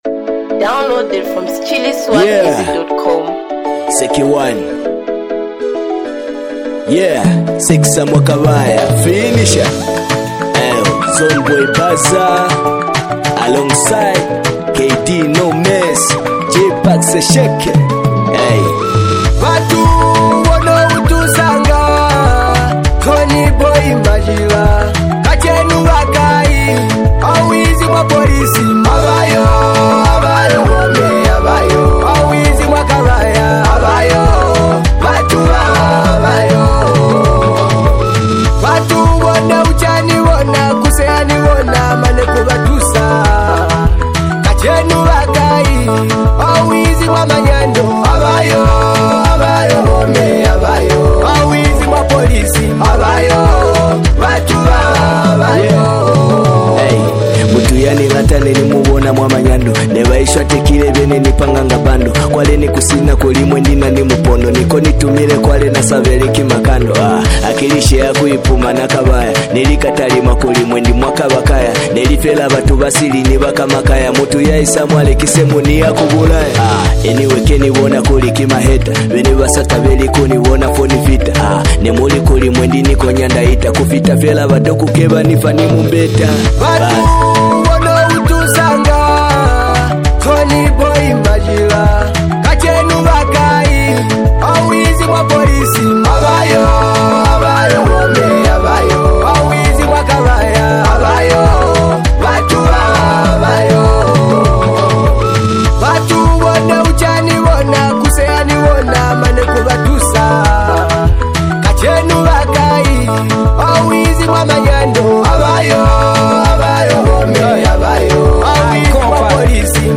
Latest Zambia Afro-Beats Single (2026)
Genre: Afro-Beats